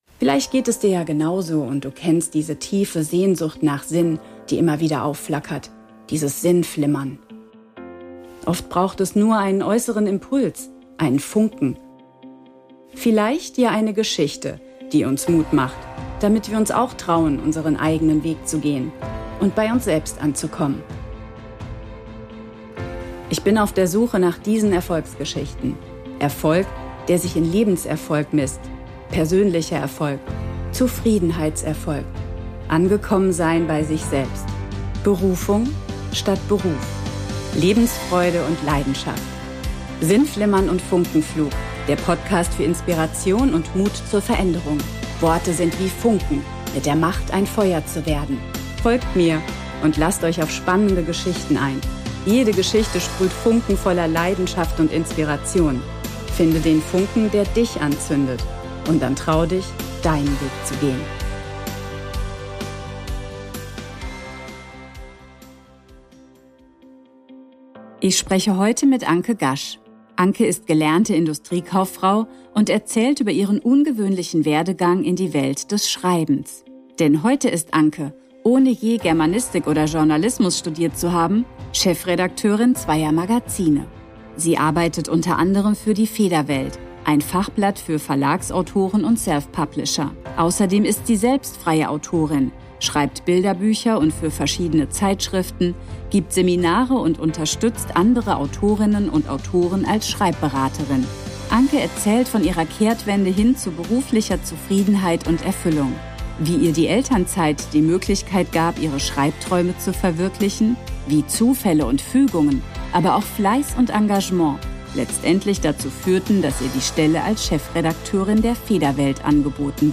#009 Interview